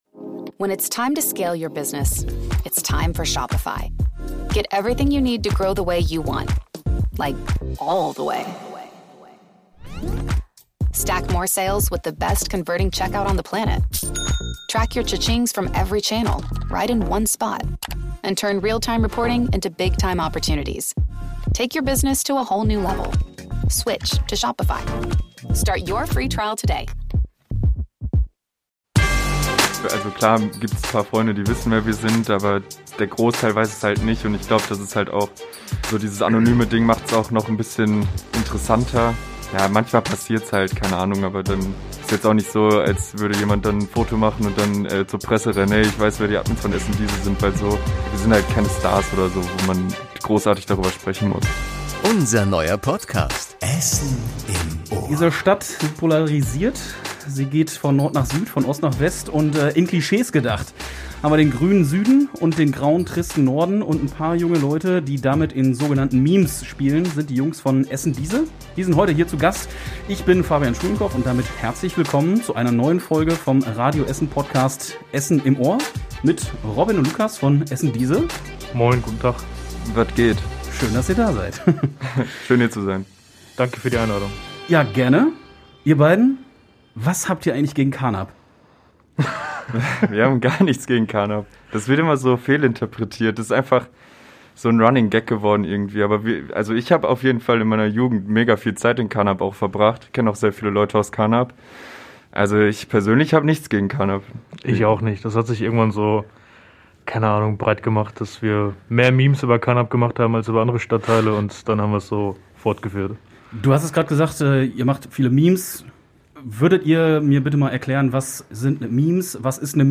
#52 mit essendiese ~ Essen im Ohr - Der Talk mit Persönlichkeiten aus der Stadt Podcast